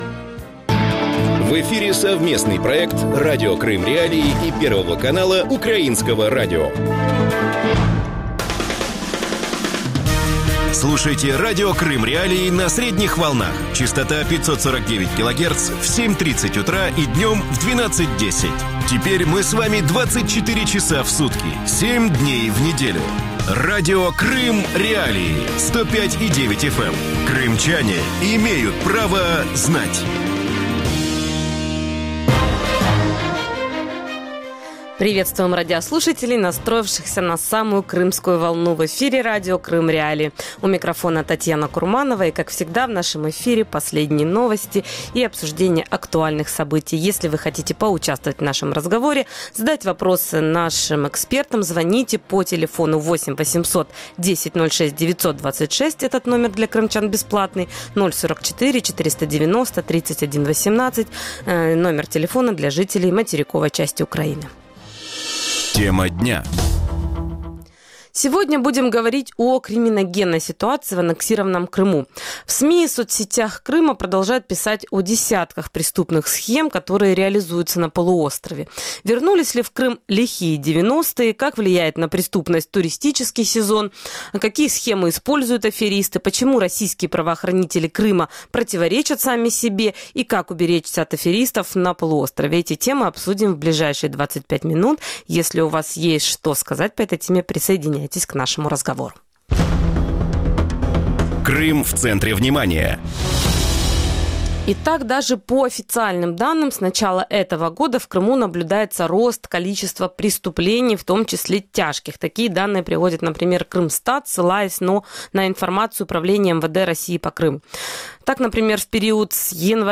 Николай Паламарчук, бывший начальник Главного управления МВД Украины в АРК